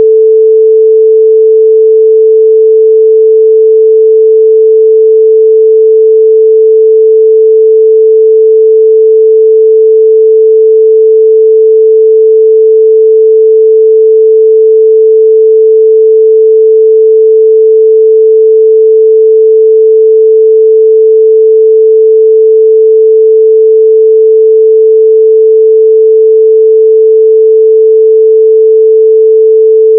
Le premier cliquer_ici  "perturbation.wav"est un son perturbateur.
perturbation.wav